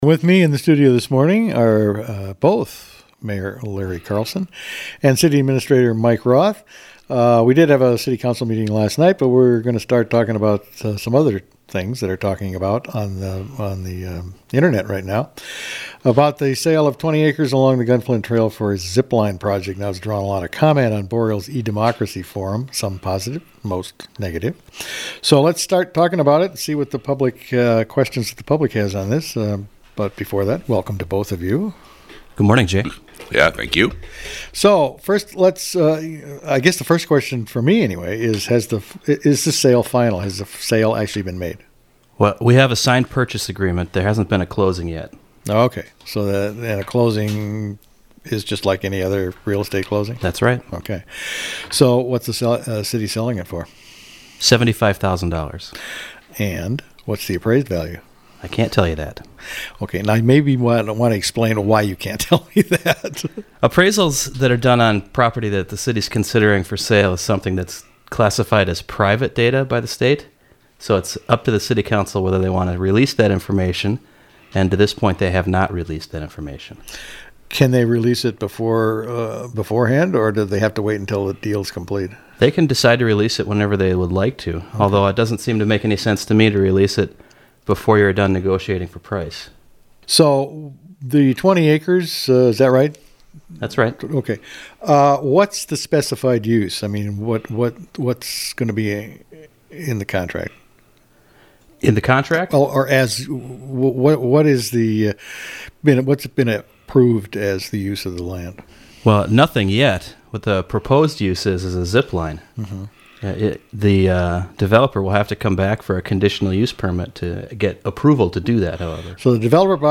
Officials talk about the zipline property purchase and other Grand Marais issues